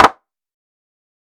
TC3Clap6.wav